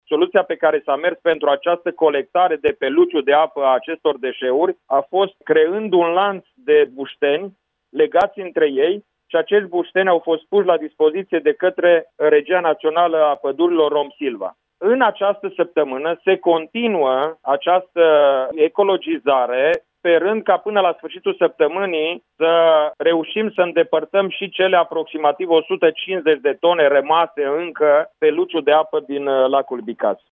Ministrul Apelor şi Pădurilor, Ioan Deneş, a declarat, astăzi, în emisiunea Tema Zilei de la Radio Iaşi, că acţiunea de curăţare va continua şi săptămâna aceasta: